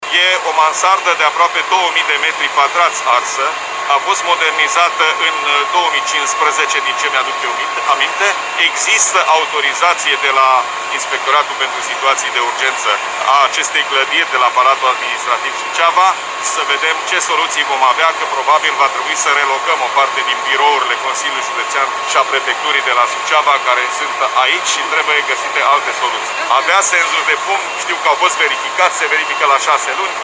Președintele Consiliului Județean GHEORGHE FLUTUR a declarat că imobilul avea autorizaţie ISU și că o parte din birouri vor trebui relocate pe perioada lucrărilor de refacere.